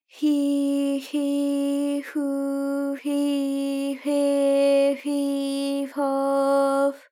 ALYS-DB-001-JPN - First Japanese UTAU vocal library of ALYS.
fi_fi_fu_fi_fe_fi_fo_f.wav